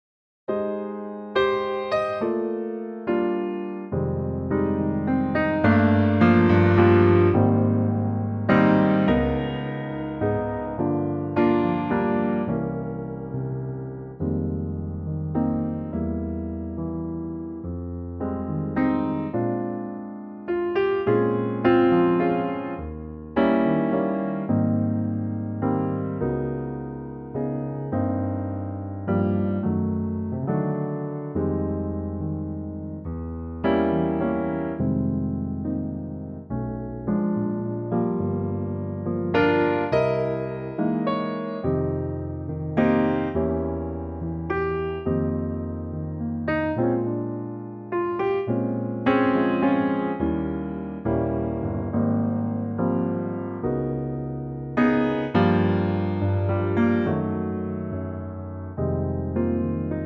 Unique Backing Tracks
key - Eb - vocal range - Bb to C
in a lovely piano only arrangement.